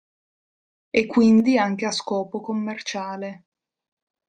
Read more Adv Conj Frequency A1 Hyphenated as quìn‧di Pronounced as (IPA) /ˈkwin.di/ Etymology From Vulgar Latin *eccum inde.